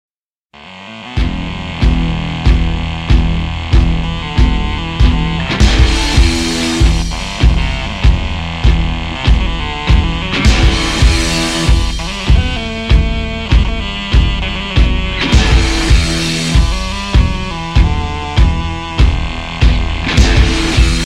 Рингтоны Без Слов
Рок Металл Рингтоны